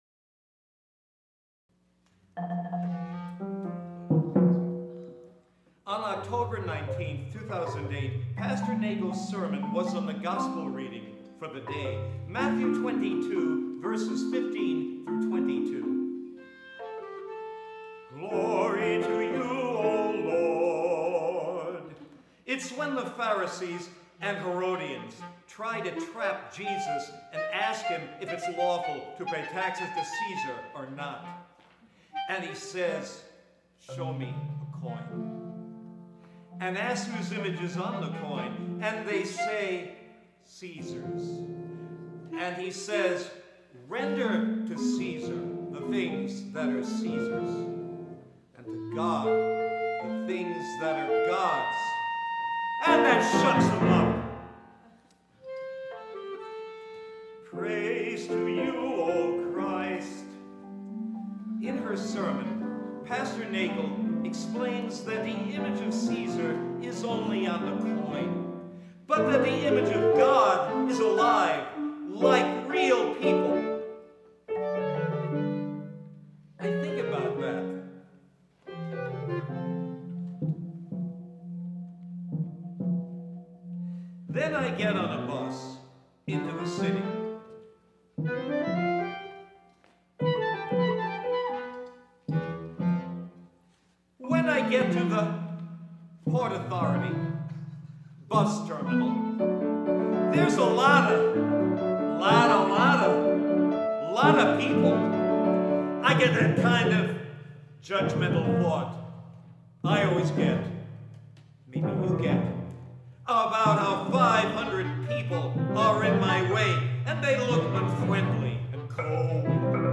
performed at the 2010
CFAMC National Conference